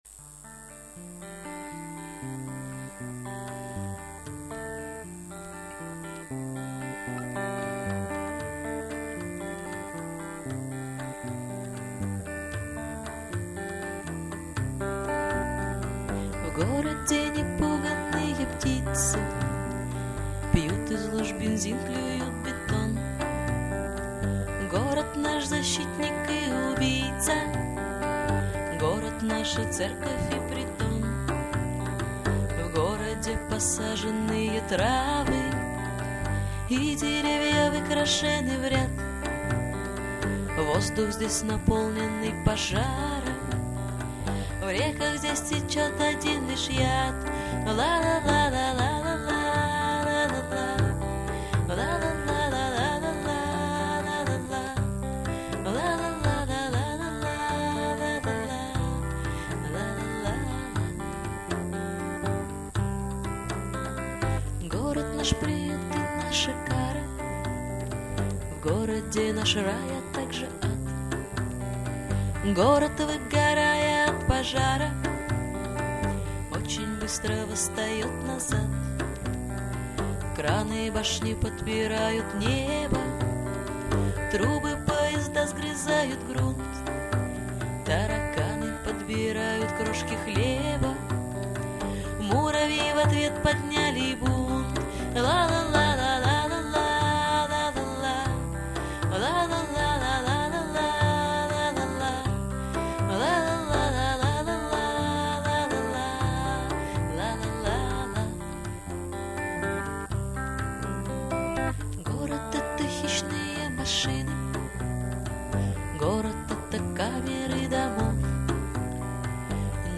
гитара
басс
перкуссия
Записано живьем в одно из февральских утр 2003.